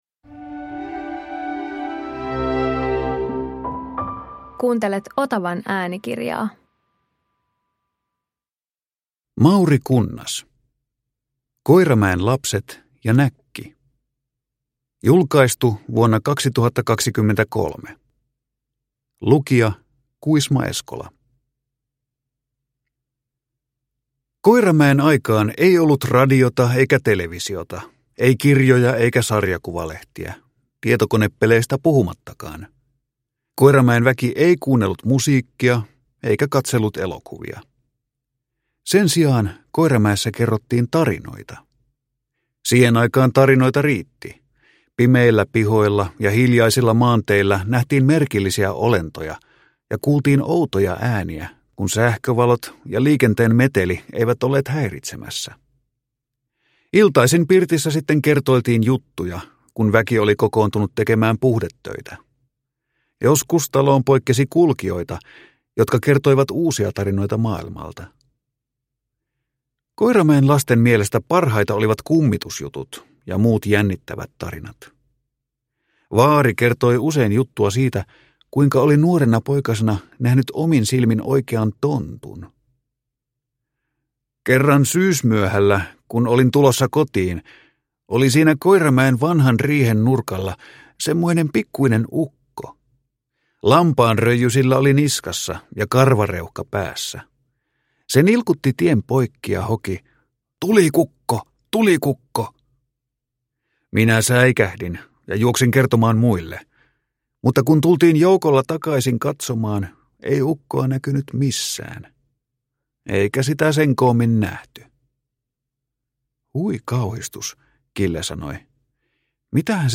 Koiramäen lapset ja näkki – Ljudbok